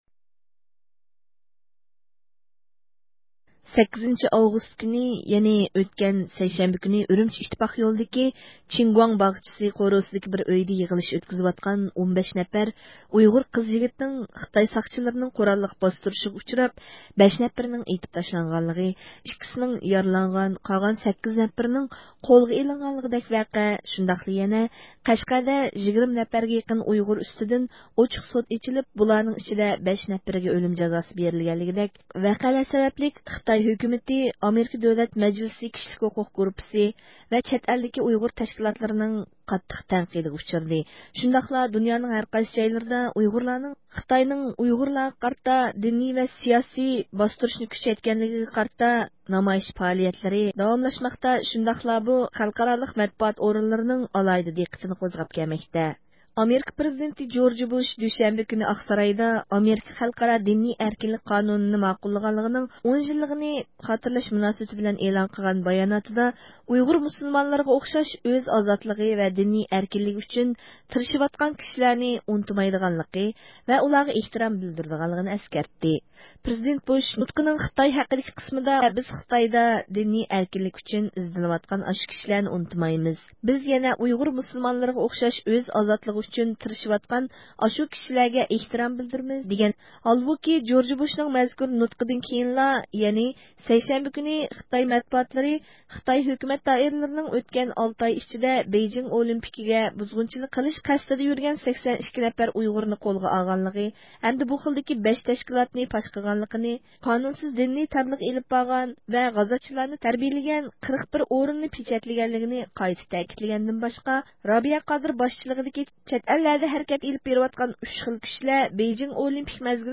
بۇ مۇناسىۋەت بىلەن بىز ئۇيغۇر مىللىي ھەرىكىتى رەھبىرى رابىيە قادىرنى زىيارەت قىلدۇق.